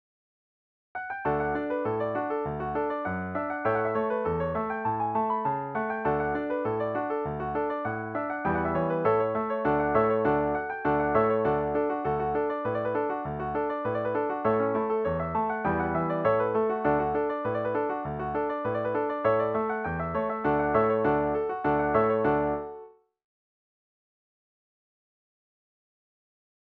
DIGITAL SHEET MUSIC - PIANO ACCORDION SOLO
Traditional Tunes, French Canadian Reel